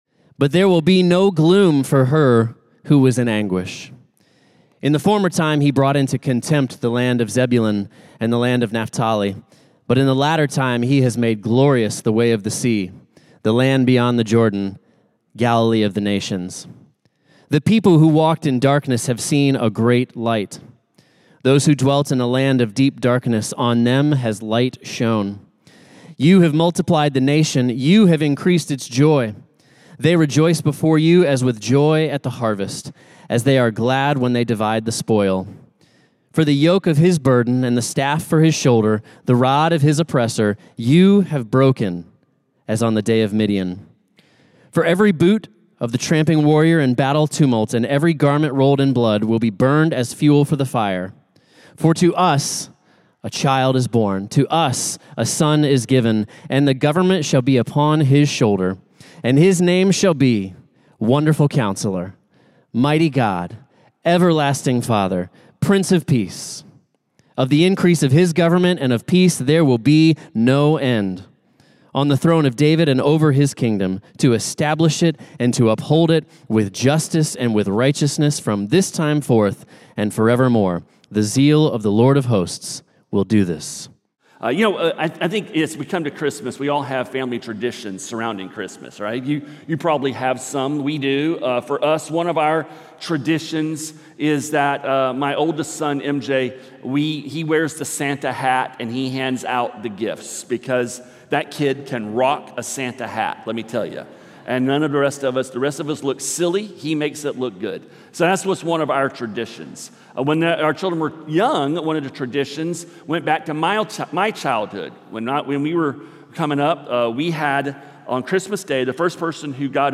A message from the series "Good News of Great Joy."